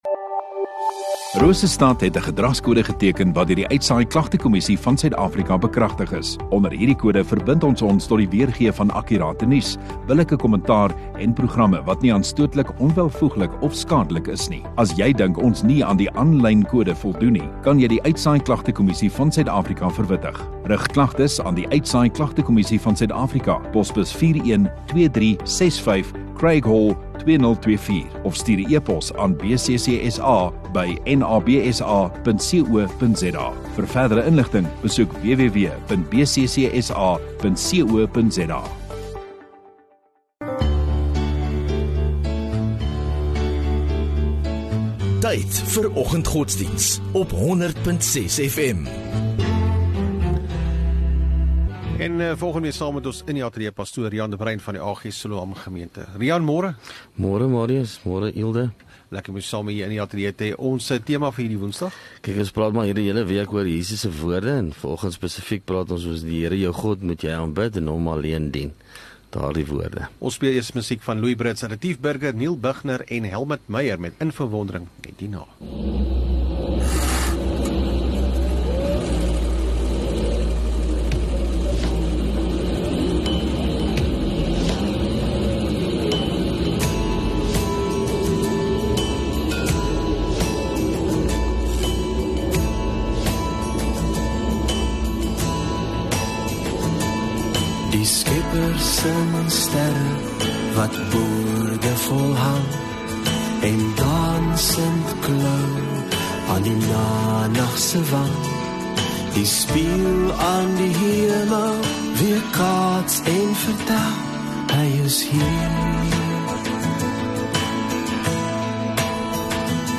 22 Jan Woensdag Oggenddiens